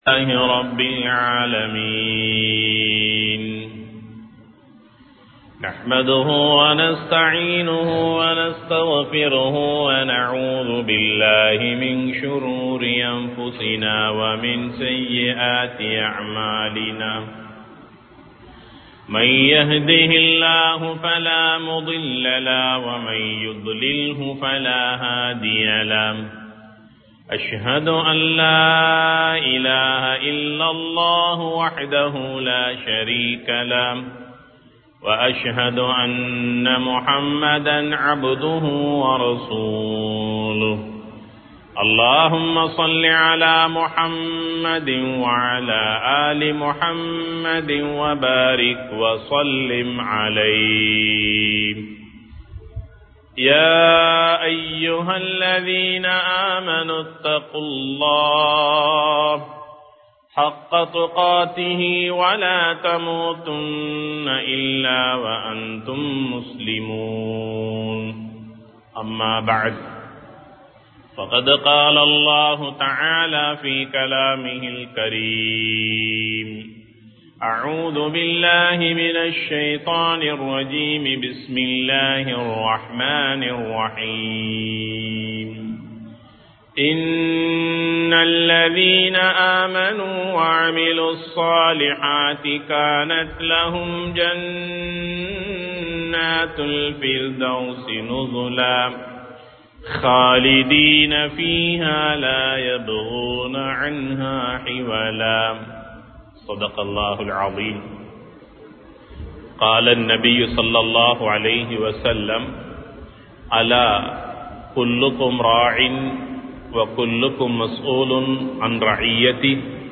சிறுவர்களுக்கும் அன்பும் பெரியோர்களுக்கு மரியாதையும் செய்யுங்கள் | Audio Bayans | All Ceylon Muslim Youth Community | Addalaichenai